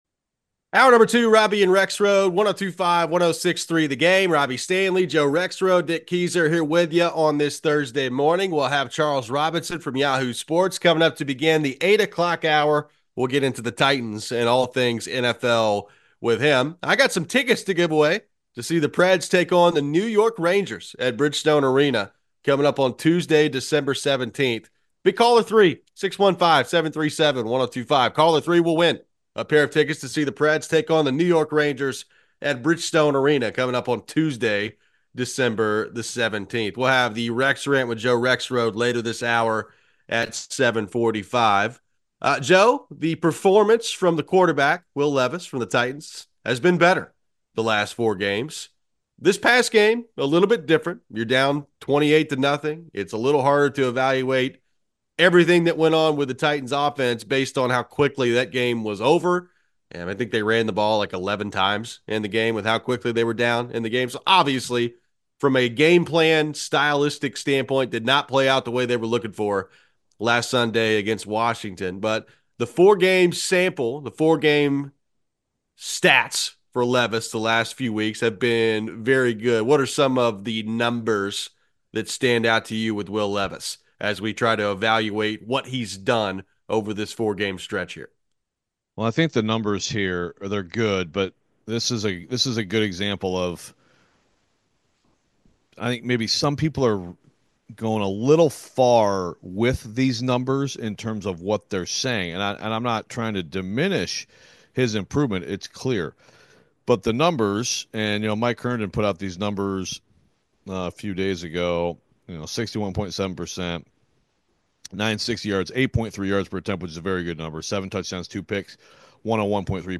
He's been playing more consistently since returning from his shoulder injury. Fans share their thoughts with the guys as well.